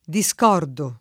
[ di S k 0 rdo ]